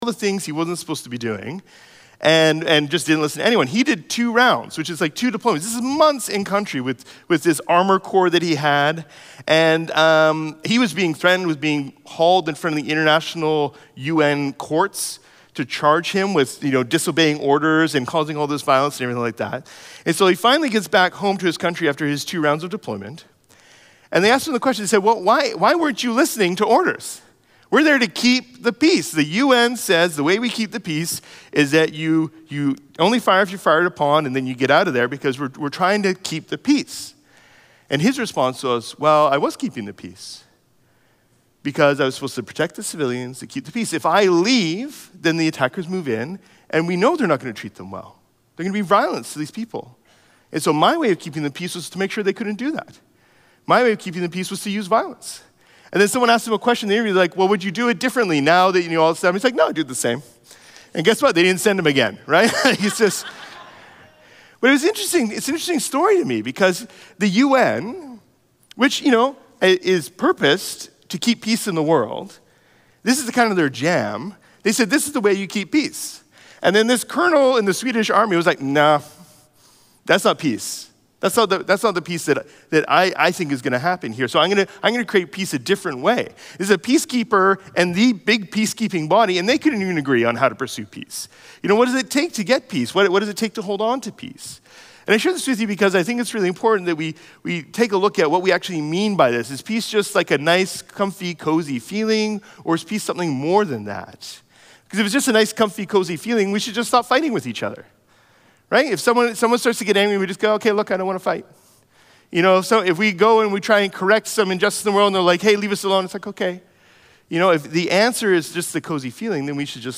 Sermons | Riverside Community Church